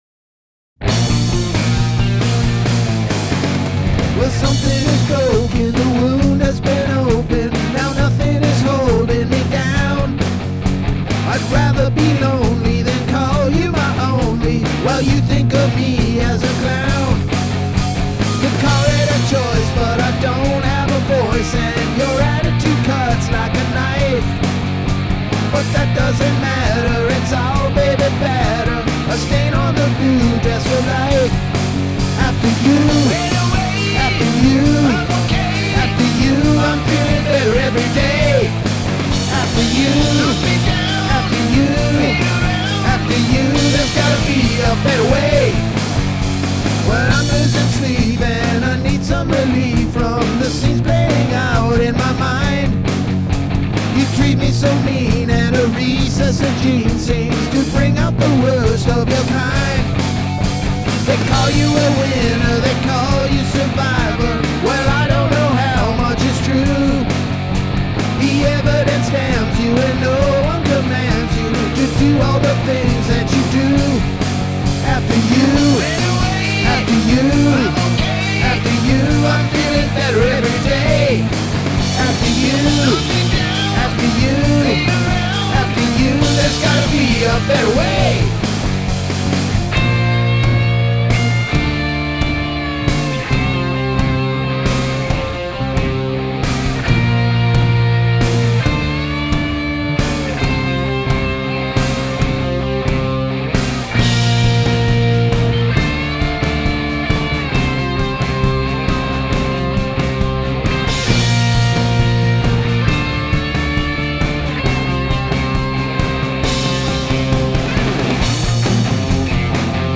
Counter Melody
Cool solo by the way!